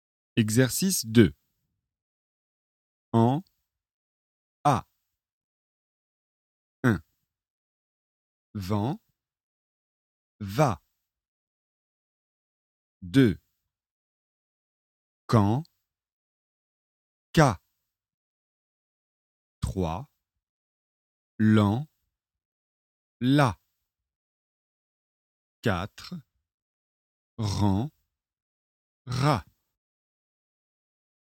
Le son [ɑ̃] est une voyelle nasale.